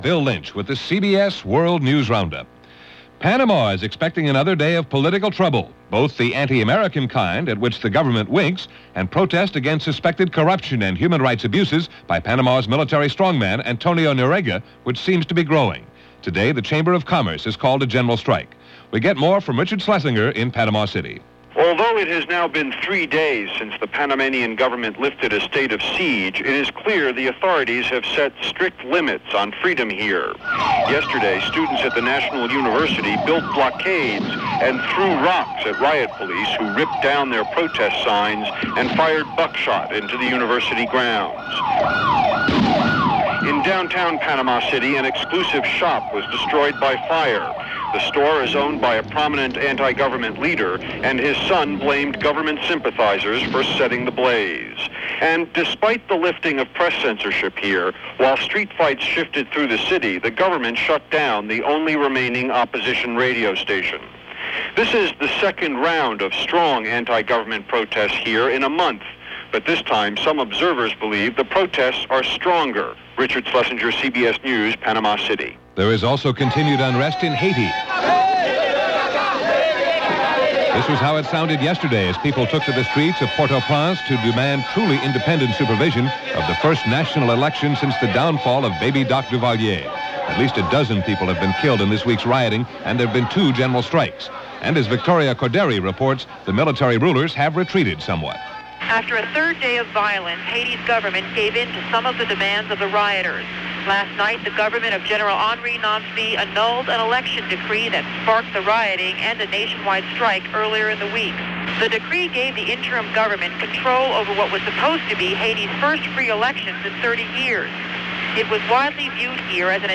CBS World News Roundup
All that, and even parts where it was peaceful, this July 3, 1987 as reported by The CBS World News Roundup.